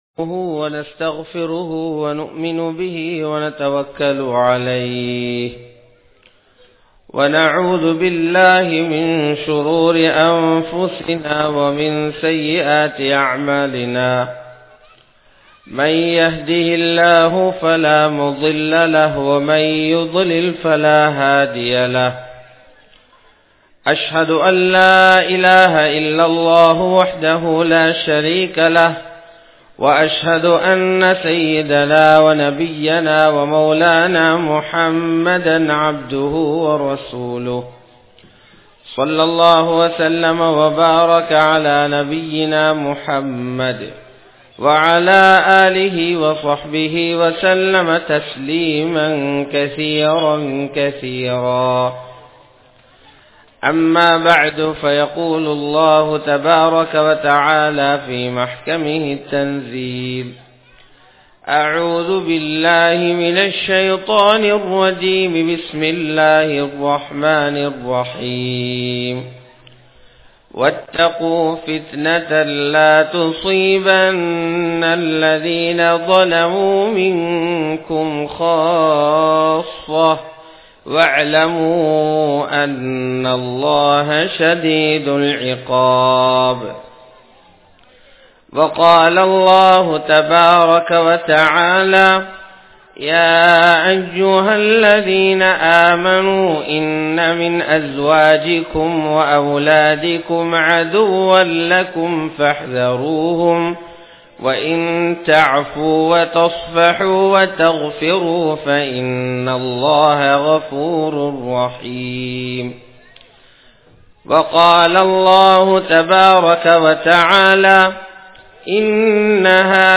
Ungalai Suvarkaththitku Kondu Sellum Al Quran (உங்களை சுவர்க்கத்திற்கு கொண்டு செல்லும் அல்குர்ஆன்) | Audio Bayans | All Ceylon Muslim Youth Community | Addalaichenai
Ansari Jumua Masjith